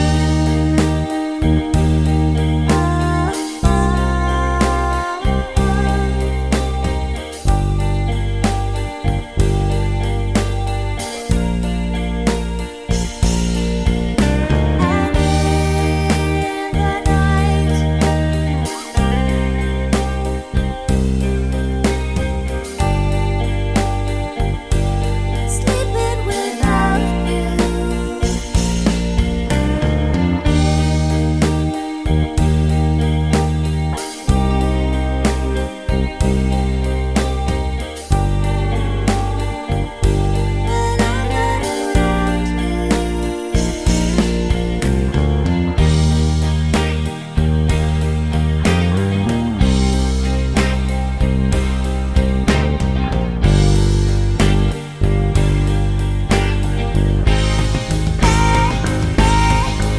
no Backing Vocals Irish 4:00 Buy £1.50